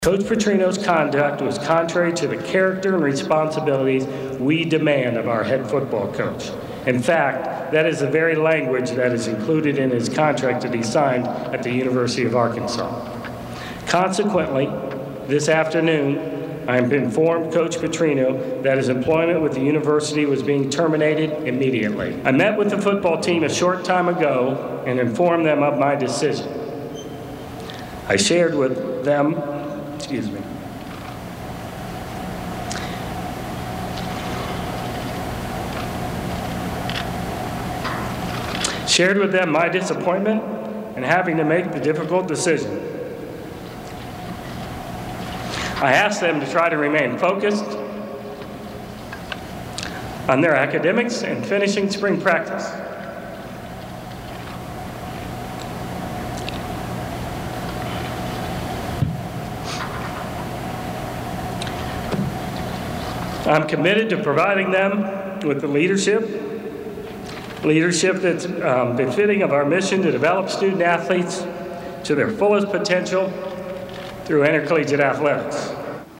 AUDIO Clip of Jeff Long’s announcement (1:30)